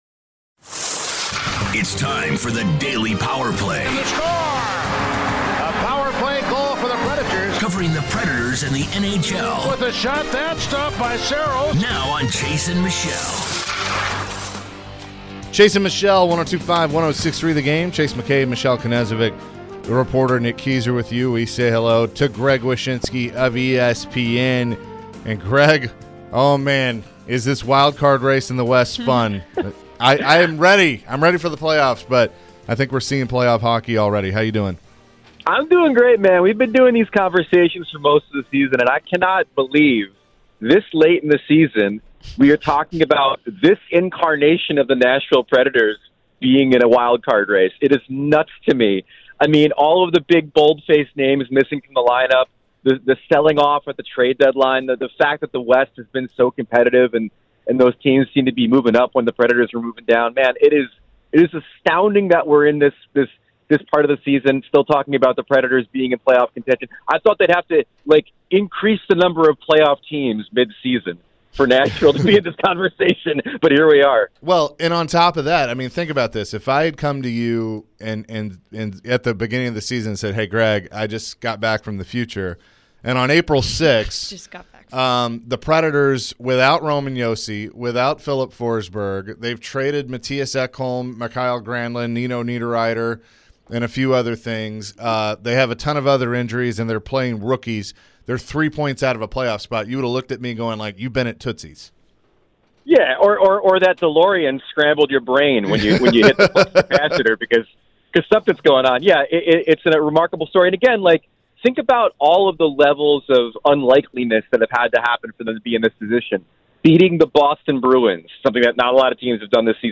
Greg Wyshynski Interview (4-6-23)